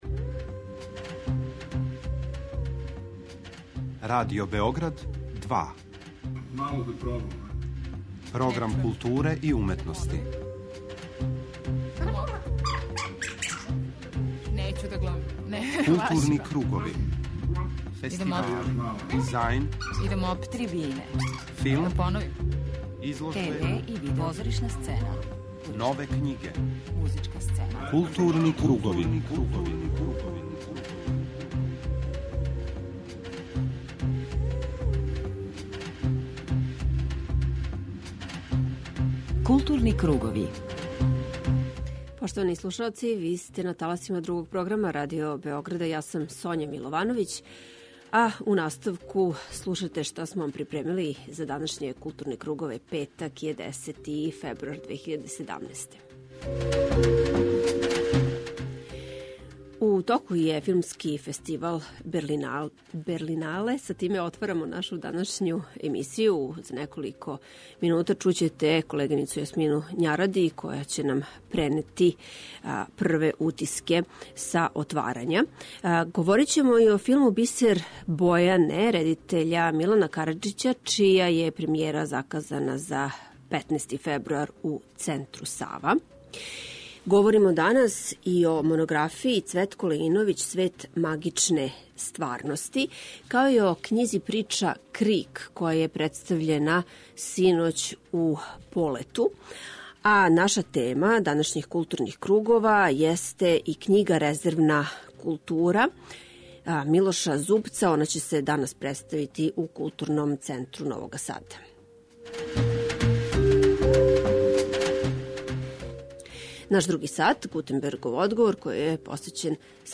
преузми : 41.16 MB Културни кругови Autor: Група аутора Централна културно-уметничка емисија Радио Београда 2.